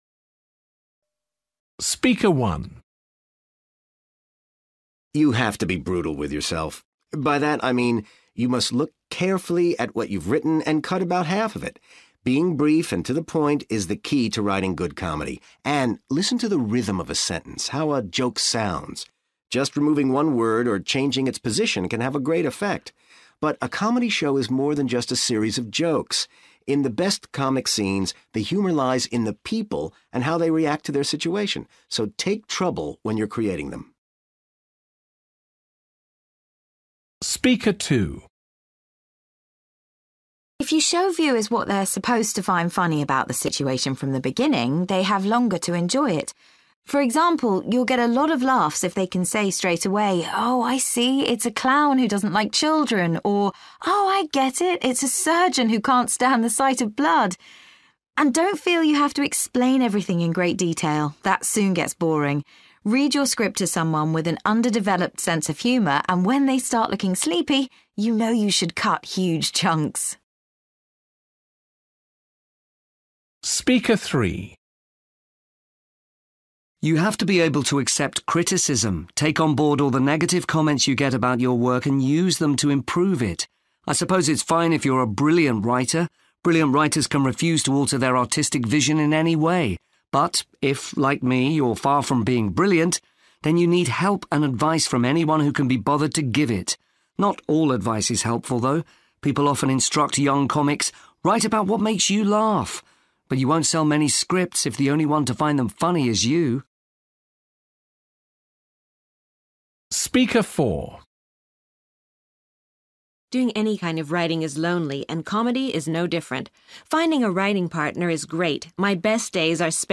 You will hear five short extracts in which writers give advice about writing comedy scripts for television.